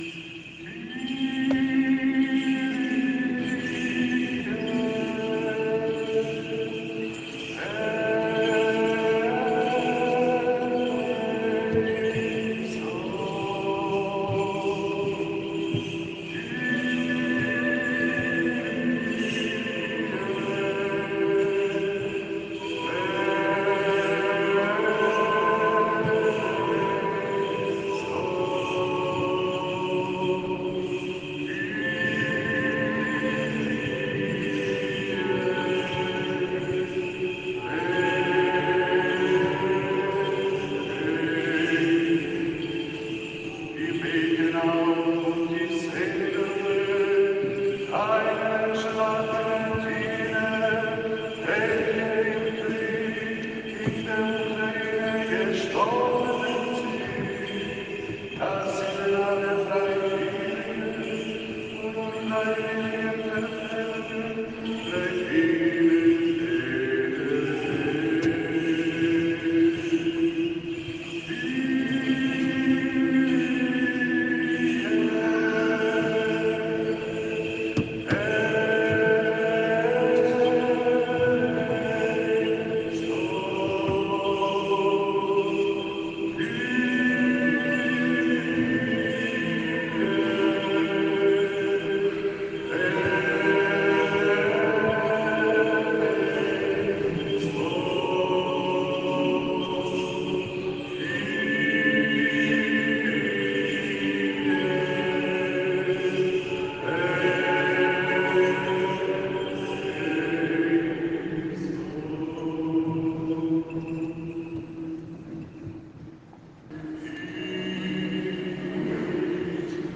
Das Unfassbare des Krieges in der Ukraine und der Ruf an Gott sind so dicht in den liturgischen Gesängen der griechisch-orthodoxen Liturgie zum Gedenken an die Toten zu spüren...
HIER EINE AKUSTISCHE EINLADUNG (Mitschnitt der Gesänge) zum MITTRAGEN des GEBETES aus der Pfarrkirche Neuottakring.